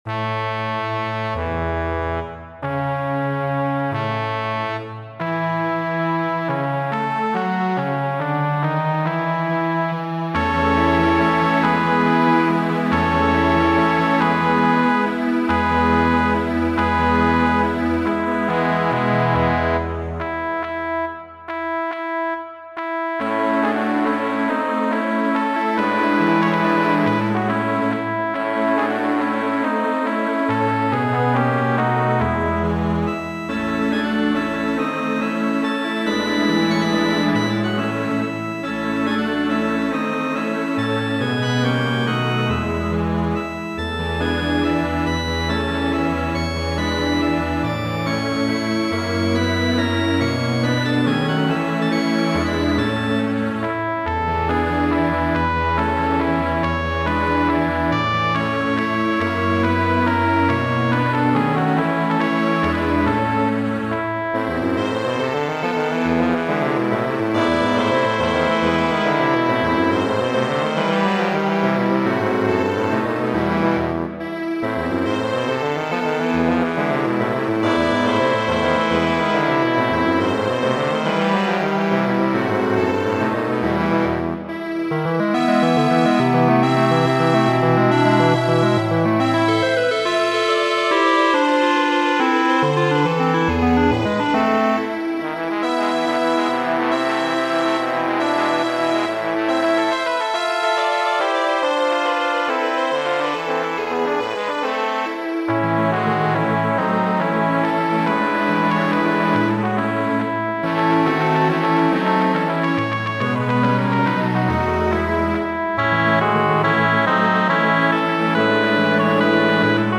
La unua paŝo al mi, porgitara duopo, verko 53-a de Fernando Sor, orĥestrigita de mi mem.